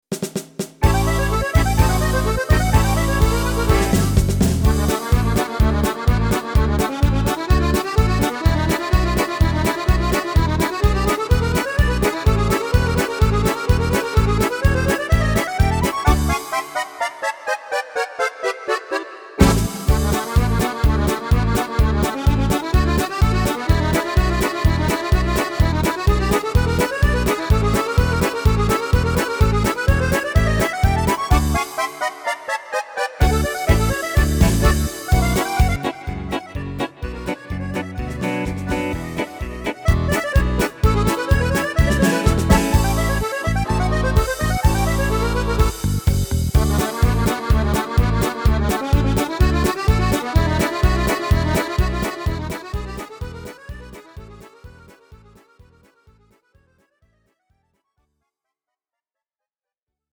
Tempo: 126 / Tonart: D – Dur